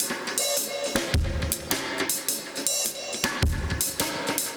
Index of /musicradar/dub-designer-samples/105bpm/Beats